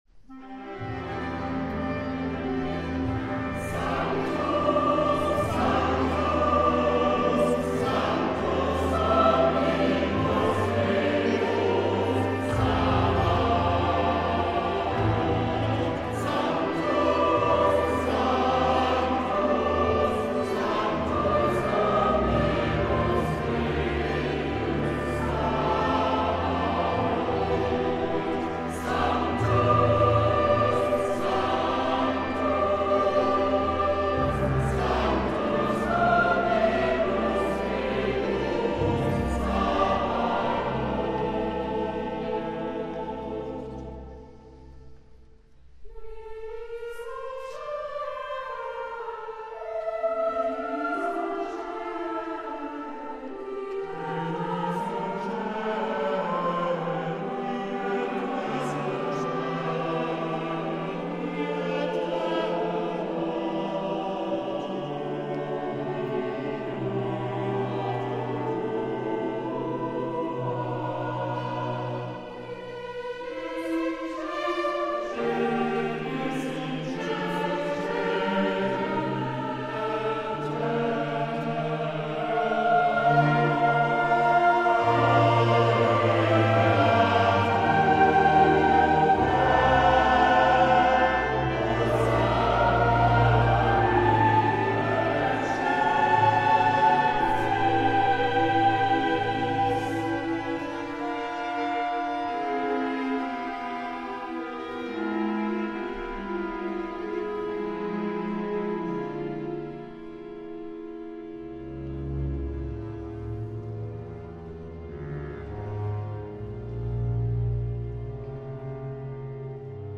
H�rbeispiele (Mitschnitt der Urauff�hrung) MP3 IV.